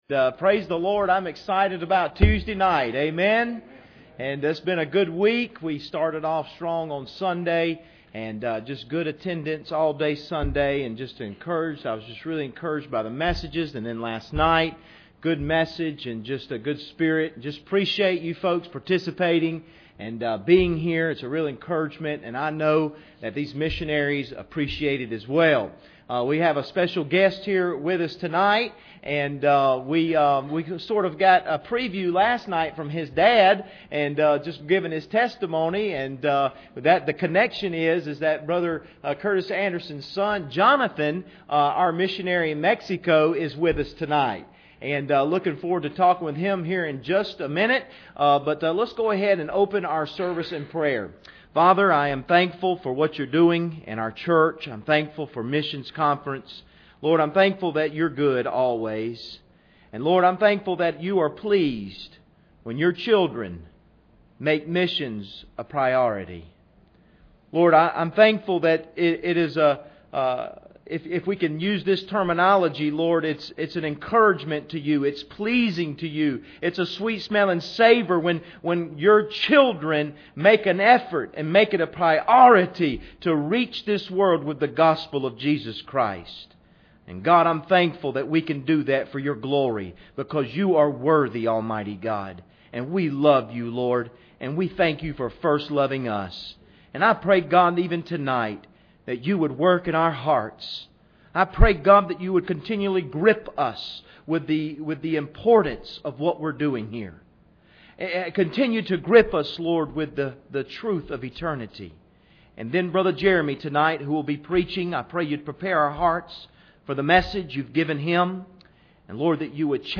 2016 Missions Conference Service Type: Special Service Preacher